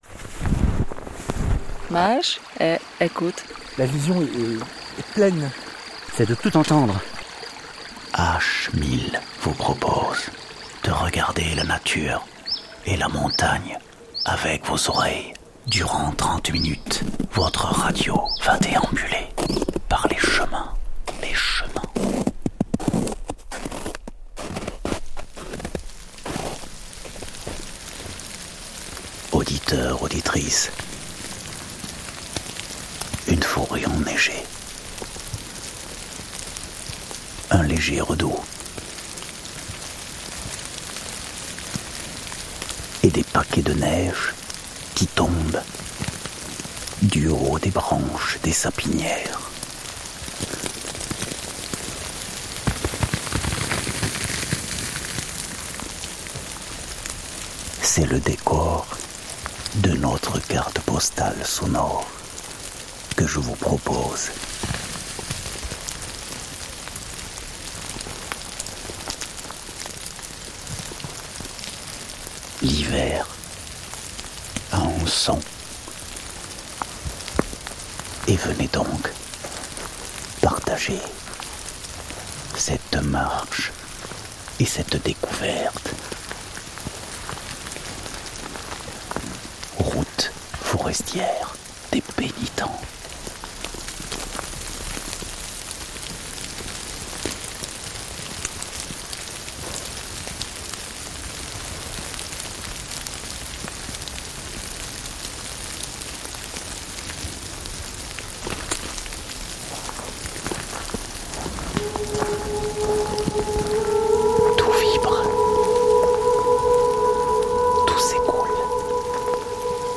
Cette semaine dans H1000, découvrez une carte postale auditive d'une balade hivernale en moyenne montagne !